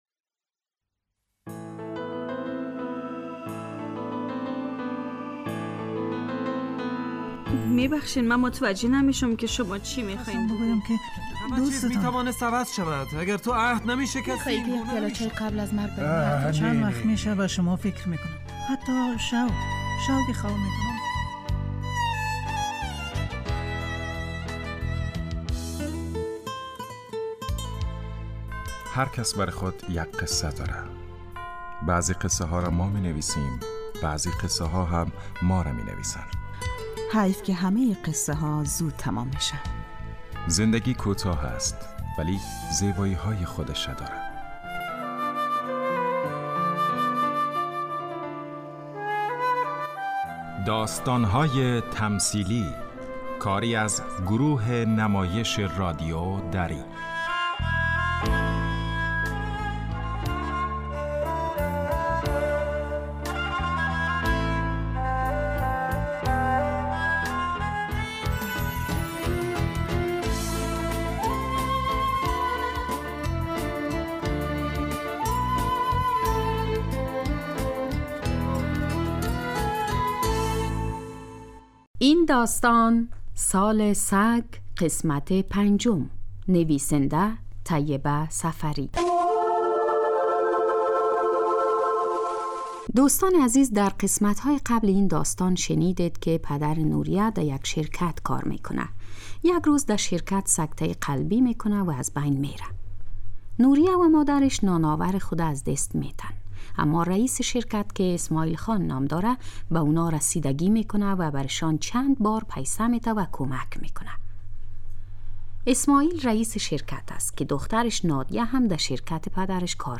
داستان تمثیلی / سال سگ